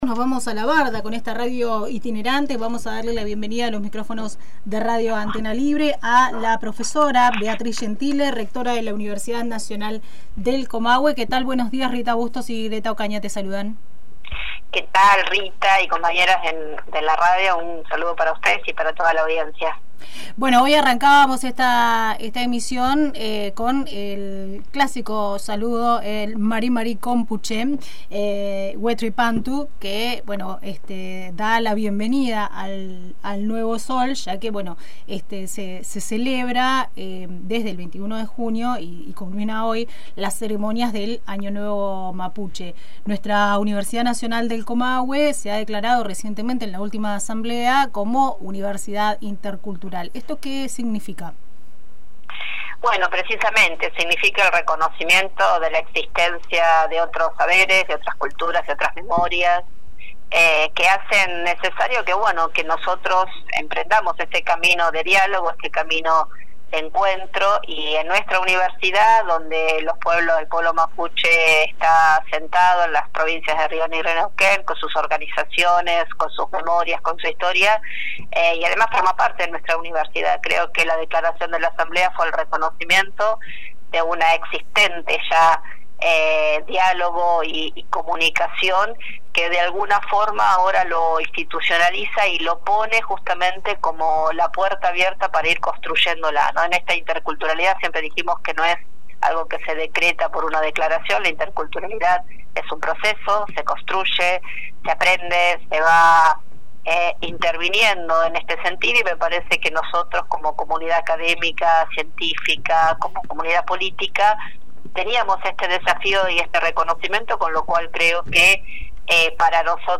En diálogo con Antena Libre, la rectora de la universidad Beatriz Gentile, explicó la importancia de reafirmar la esencia y el espíritu intercultural de la UNCO.